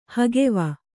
♪ hageva